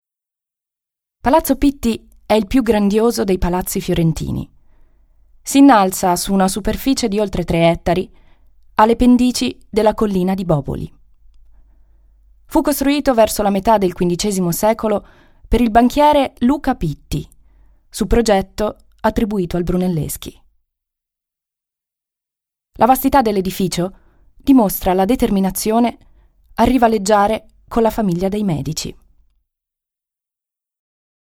Italienische Sprecherin/Synchronsprecherin, deutsch mit Akzent, Werbung,Hörbuch,Fitness,Industrie,e-lerning,Hörspiel, Voice-over, Moderatorin.
Sprechprobe: Industrie (Muttersprache):